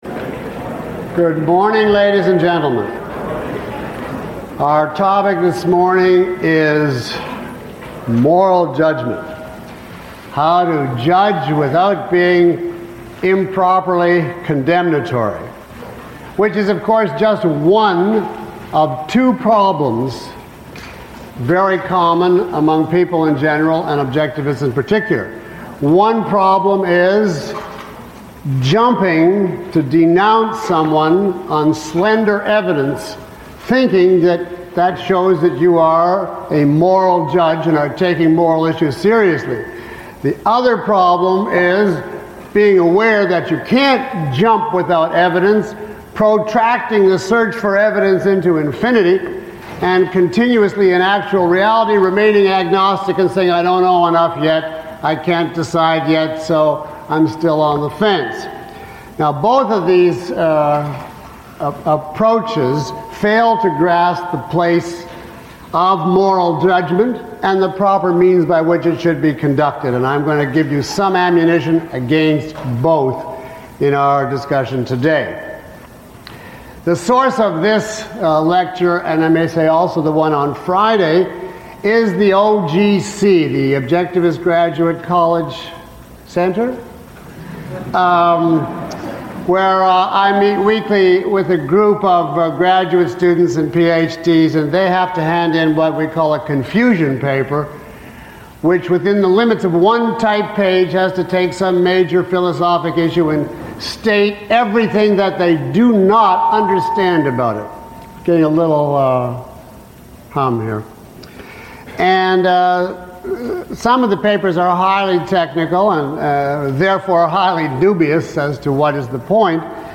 Lecture 01 - Judging Feeling and Not Being Moralistic.mp3